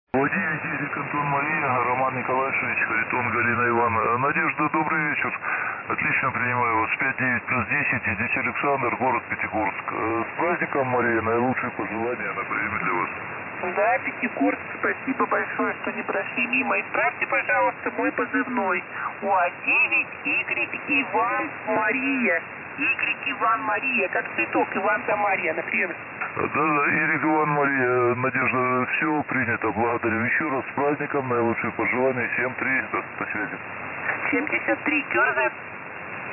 Включил напрямую на балконную mfj-1620 под SDR Console
На удивление, без всяких преселекторов неплохо звучит.